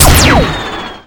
gun2.ogg